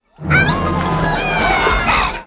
Ewok yell
ewok-karate.wav